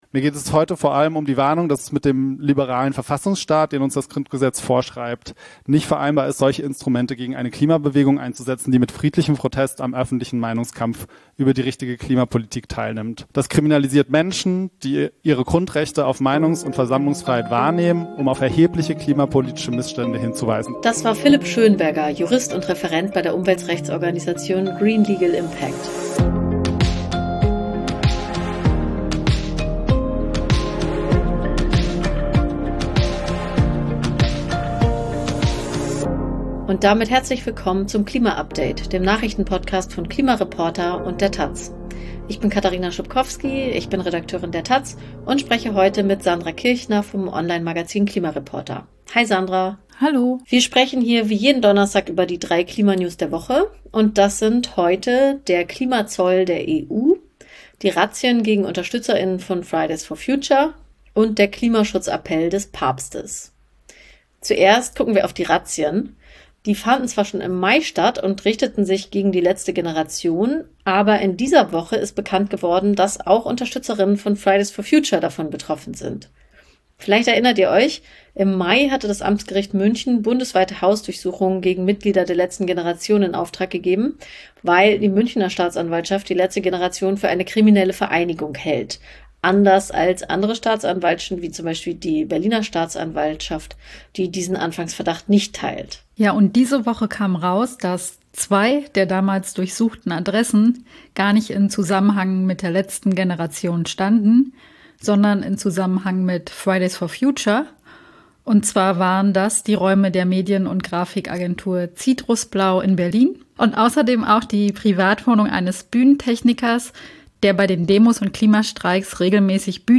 Im klima update° besprechen Journalistinnen vom Online-Magazin klimareporter° und von der Tageszeitung taz jeden Freitag die wichtigsten Klima-Nachrichten der Woche.